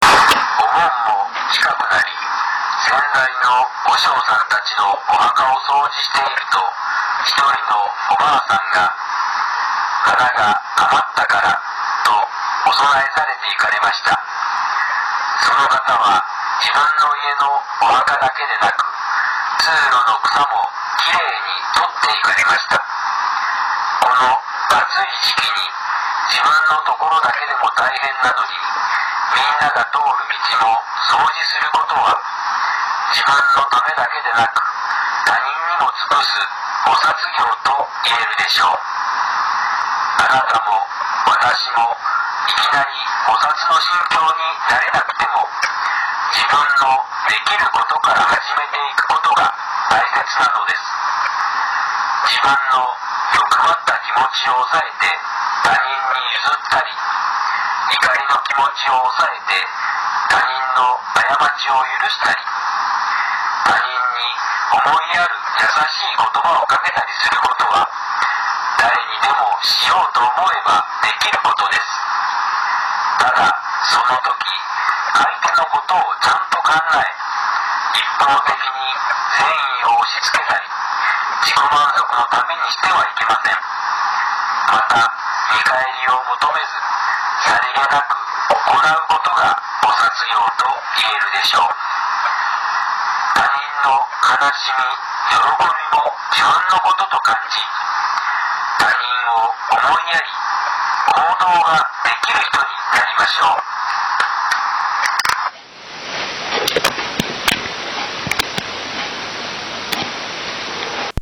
テレフォン法話
曹洞宗岐阜宗務所では電話による法話の発信を行っています。